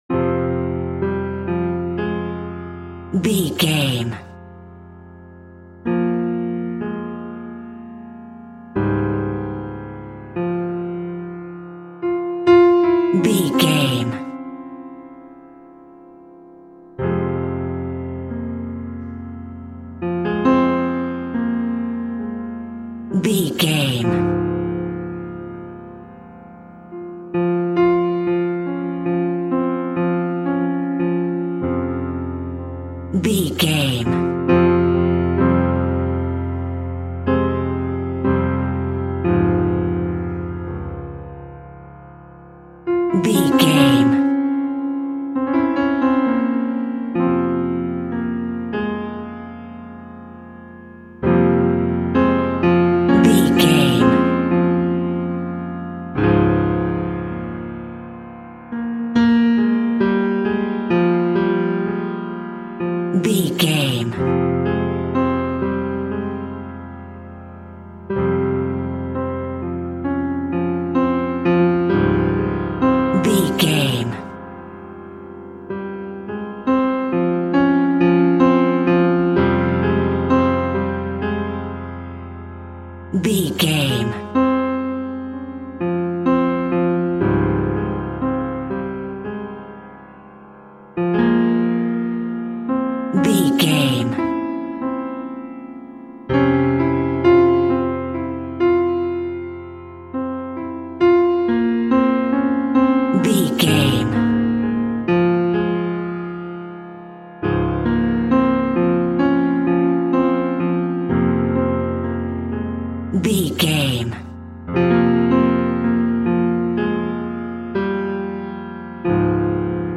Aeolian/Minor
tension
ominous
dark
suspense
haunting
eerie